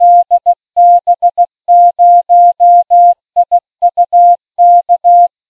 “Kennung der Funkstation”
morsecode 15WPM.mp3